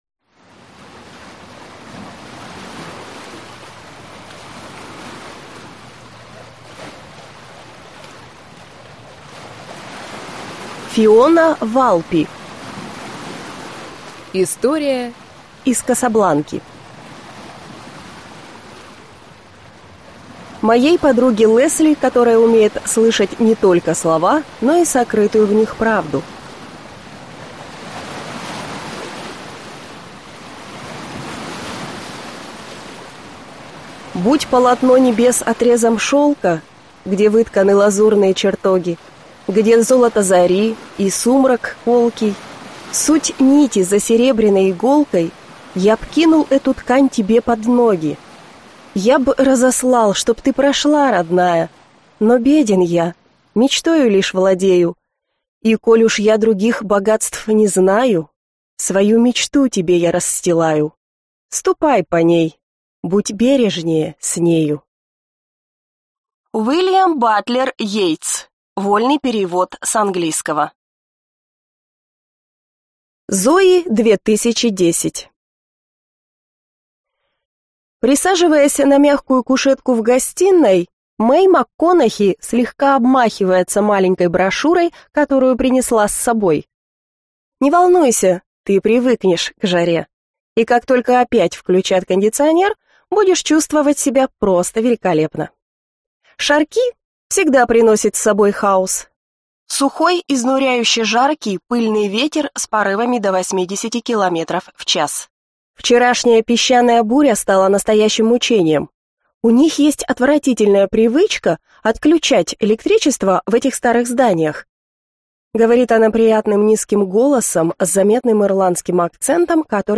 Аудиокнига История из Касабланки | Библиотека аудиокниг